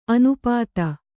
♪ anupāta